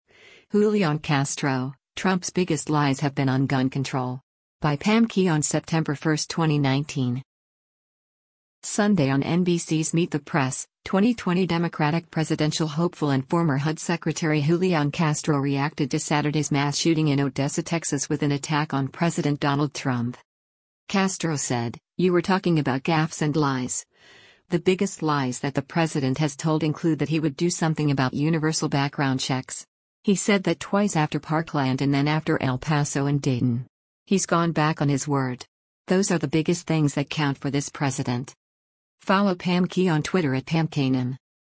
Sunday on NBC’s “Meet the Press,” 2020 Democratic presidential hopeful and former HUD Secretary Julián Castro reacted to Saturday’s mass shooting in Odessa, TX with an attack on President Donald Trump.